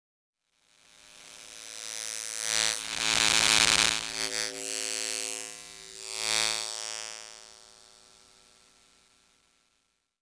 ElectricityBuzz.L.wav